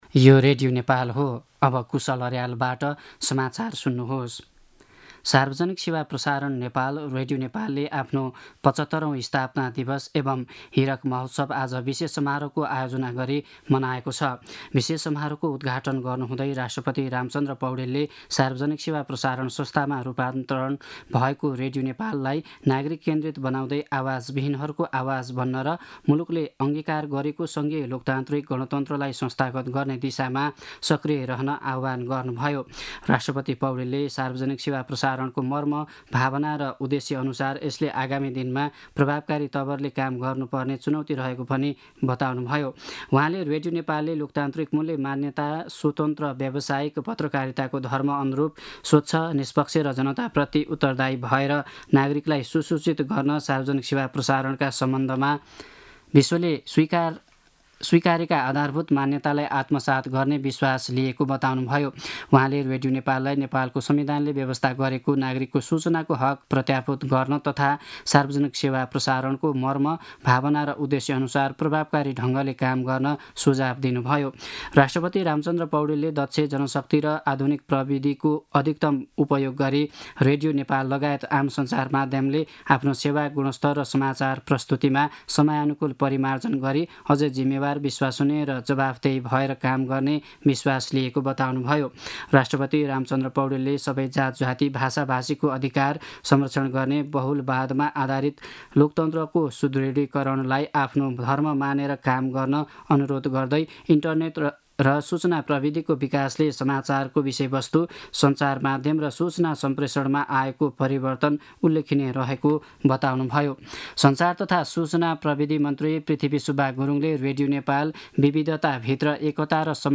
दिउँसो ४ बजेको नेपाली समाचार : २० चैत , २०८१
4-pm-News-20.mp3